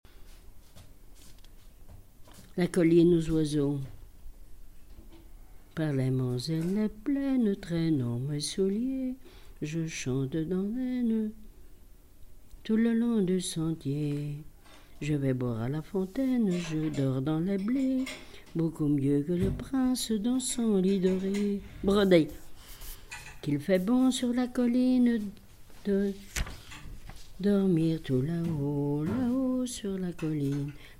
témoignage et chansons
Pièce musicale inédite